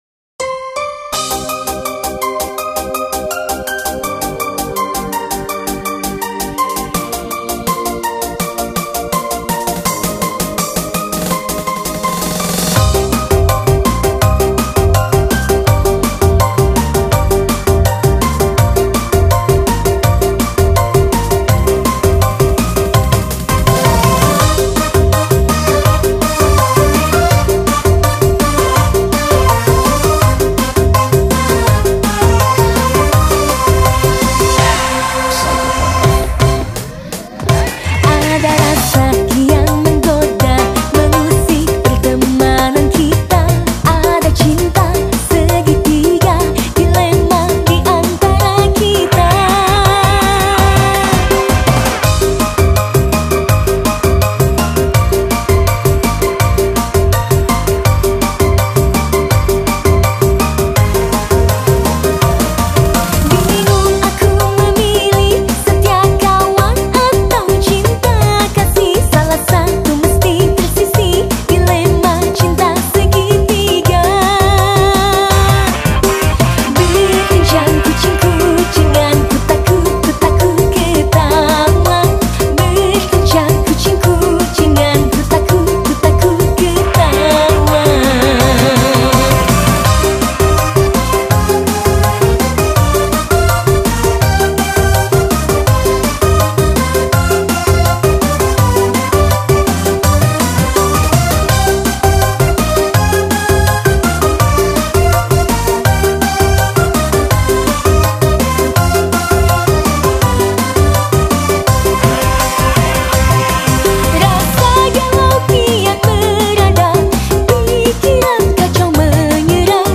Dangdut Koplo